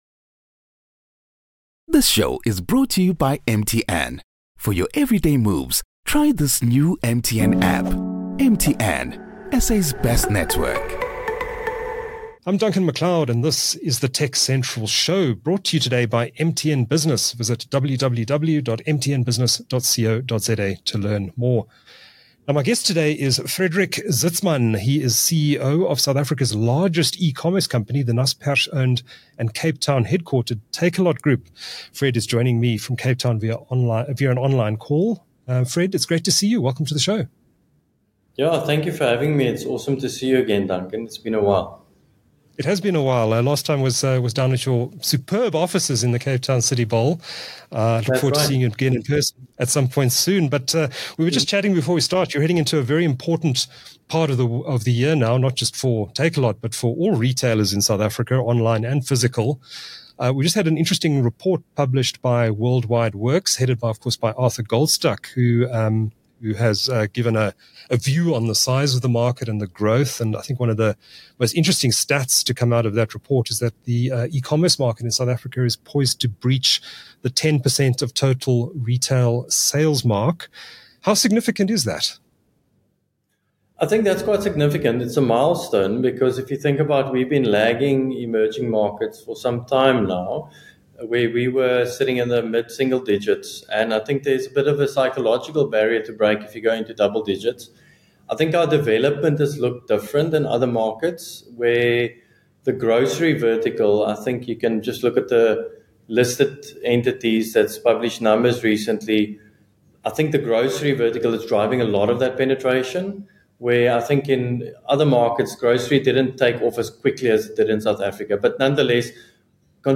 The TechCentral Show (TCS, for short) is a tech show produced by South Africa's leading technology news platform. It features interviews with newsmakers, ICT industry leaders and other interesting people.